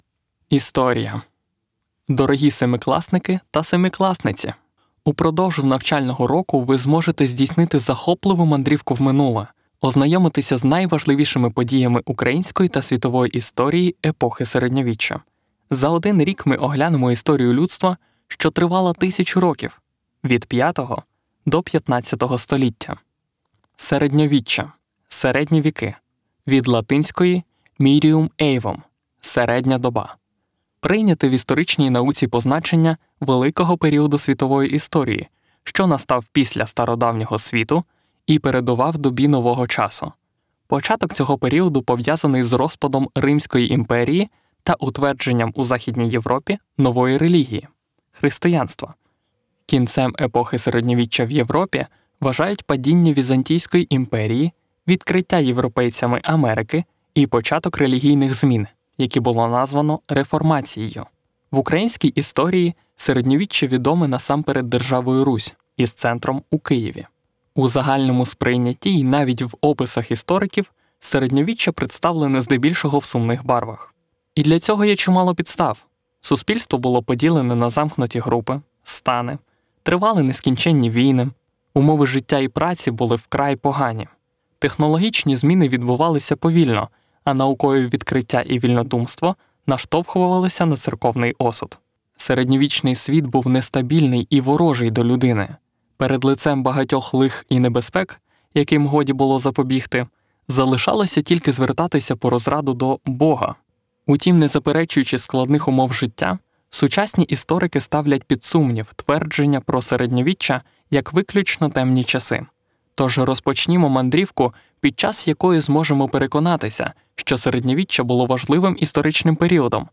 Comercial, Natural, Versátil, Amable, Travieso
E-learning
Known for his reliable, friendly, conversational and playful voice.